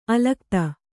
♪ alakta